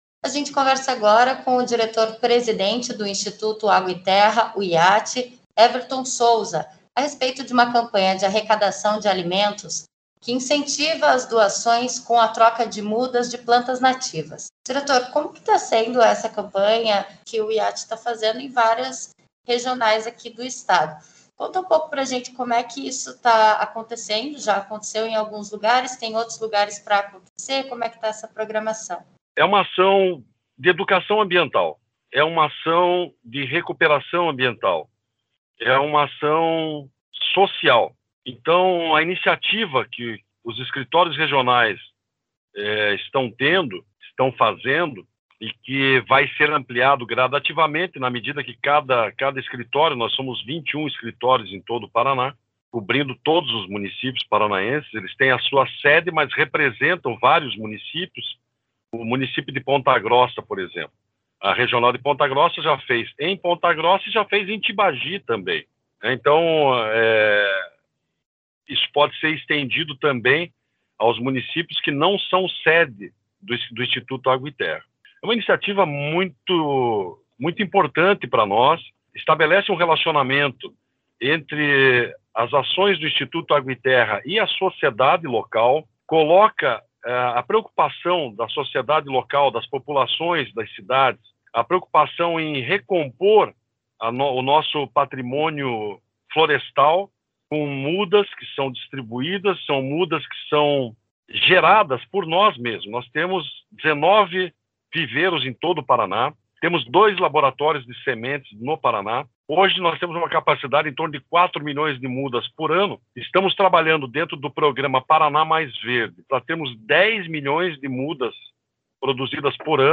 Uma iniciativa social do Instituto Água e Terra (IAT) está recolhendo doações de alimentos, roupas e ração animal e oferecendo em troca mudas de plantas nativas do Paraná. Acompanhe a entrevista